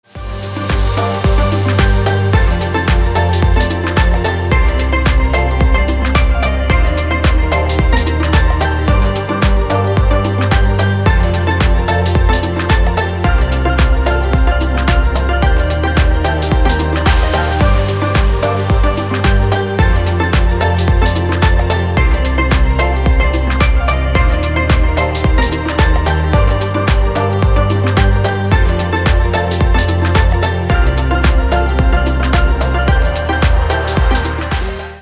красивые
dance
спокойные
без слов
chillout
Downtempo